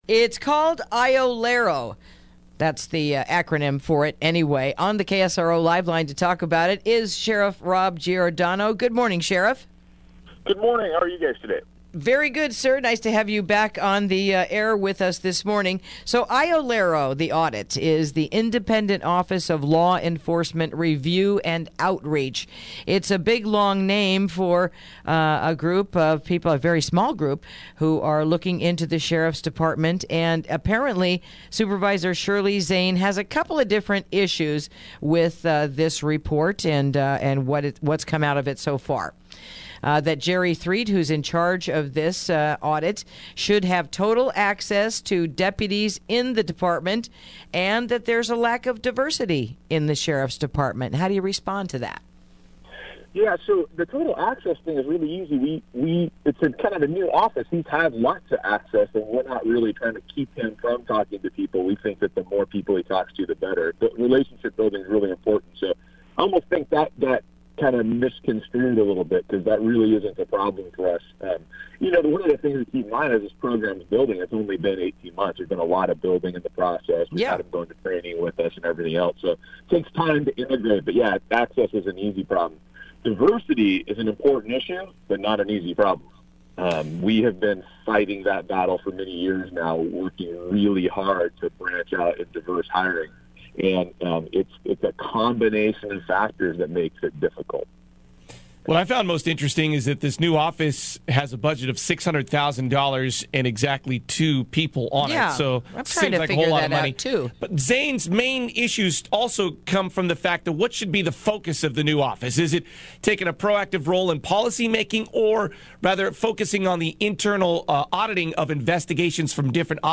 Interview: IOLERO Audit Before the Sonoma County Board of Supervisors
Sheriff Rob Giordano, joins us to talk about the Independent Office of Law Enforcement Review and Outreach audit.